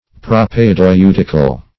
Search Result for " propaedeutical" : The Collaborative International Dictionary of English v.0.48: Propaedeutic \Pro`p[ae]*deu"tic\, Propaedeutical \Pro`p[ae]*deu"tic*al\, a. [Gr.
propaedeutical.mp3